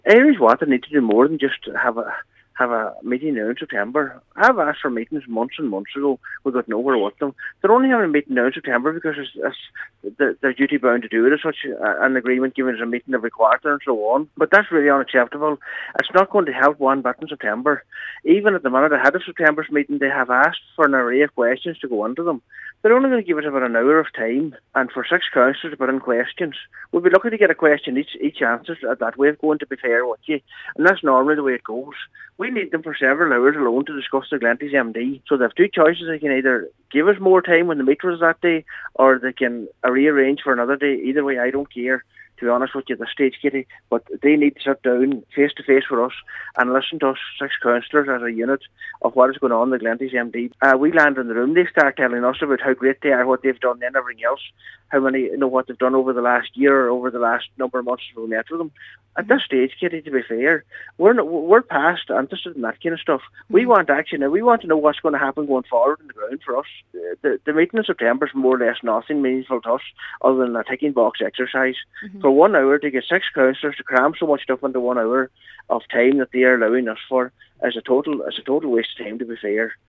Cllr McClafferty says an urgent meeting is needed and September may be too far away: